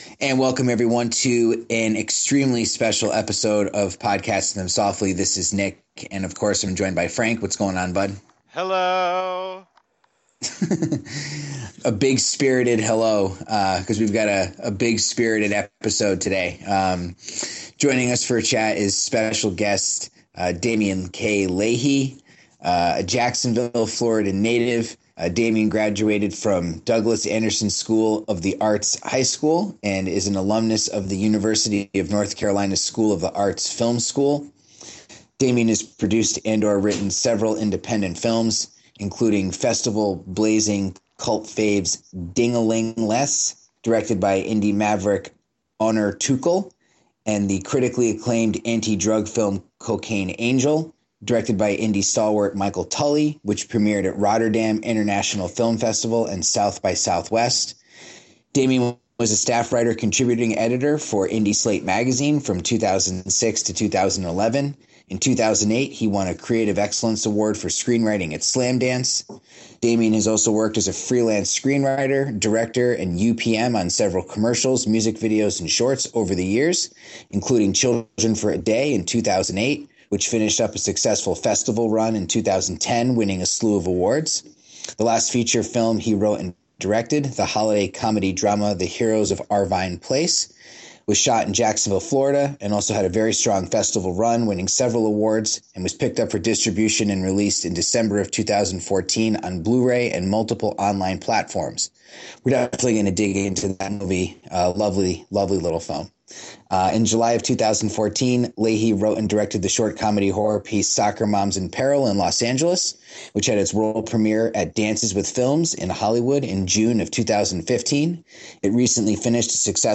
Podcasting Them Softly is thrilled to present a passionate cinematic discussion